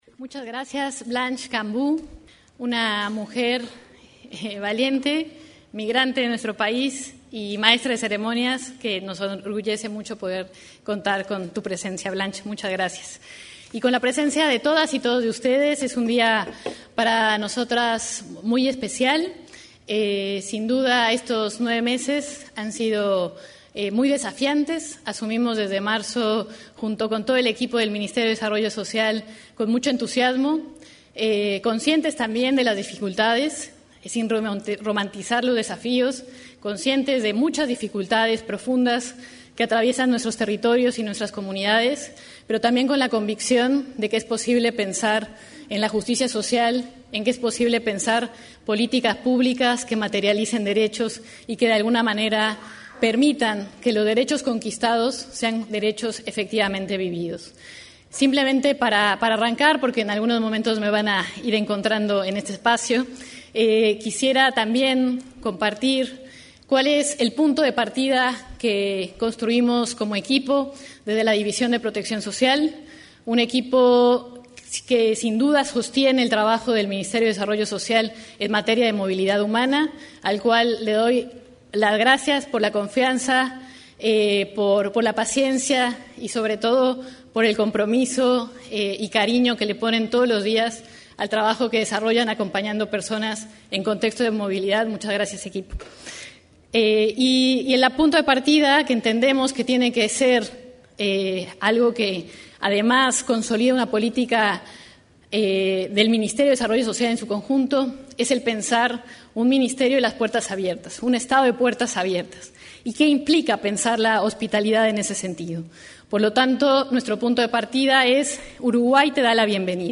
Palabras de autoridades del Ministerio de Desarrollo Social
Palabras de autoridades del Ministerio de Desarrollo Social 18/11/2025 Compartir Facebook X Copiar enlace WhatsApp LinkedIn En la presentación de las líneas de acción 2026-2030 en materia de protección social para personas migrantes, expusieron el ministro de Desarrollo Social, Gonzalo Civila, y la directora de Protección Social de Personas Migrantes, Solicitantes de Asilo y Refugiadas, Valeria España.